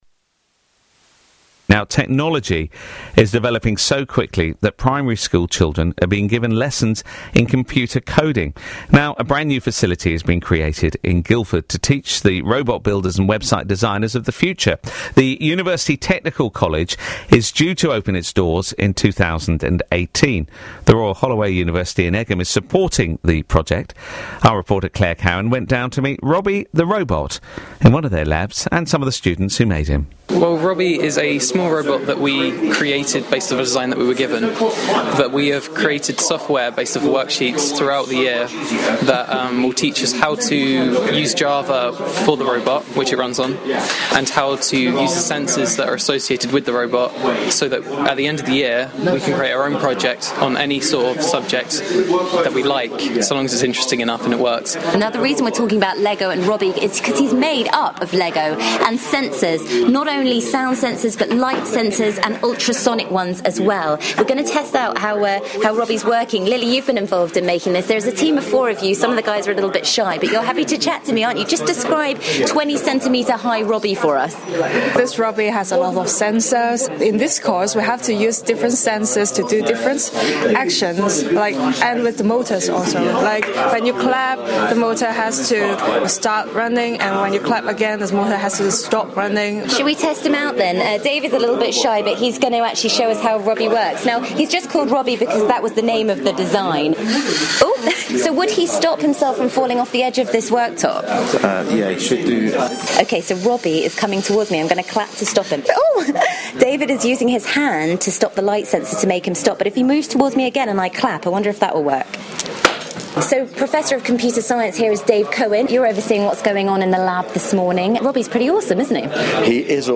Listen to BBC Surrey talk to Linda Kemeny, the county council’s Cabinet Member for Schools, Skills and Educational Achievement